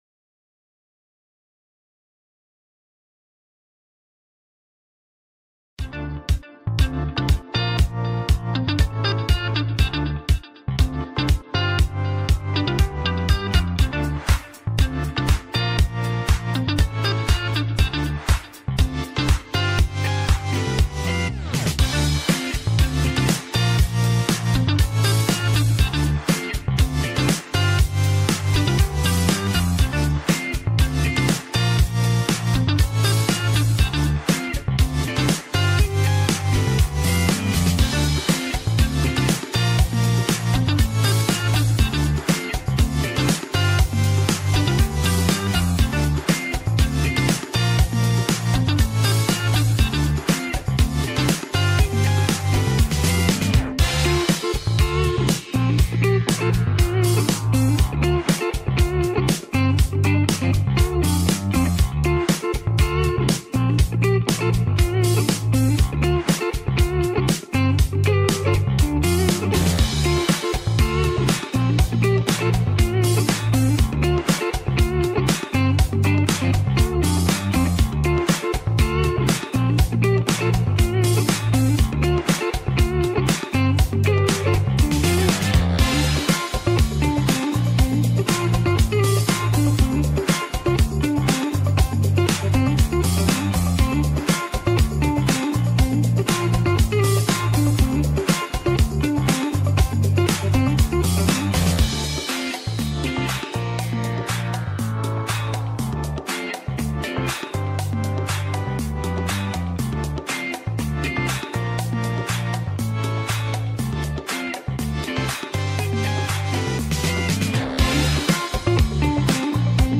Утренний эфир с гостями